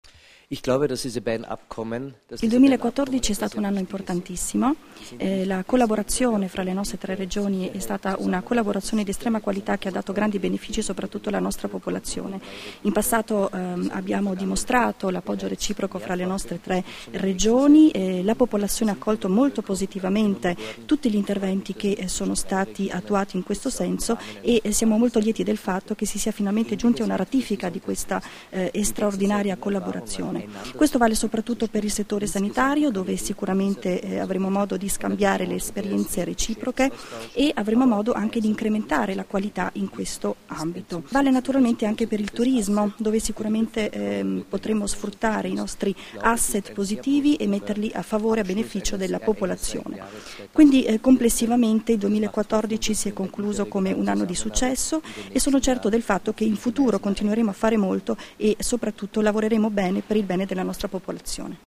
Dichiarazioni di Peter Kaiser (Formato MP3) [988KB]
a margine dell'Assemblea del Gruppo Europeo di Cooperazione Territoriale (GECT) "Euregio Senza Confini", rilasciate a Trieste il 22 dicembre 2014